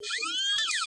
SERVO SE05.wav